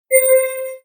alert.sonar.mp3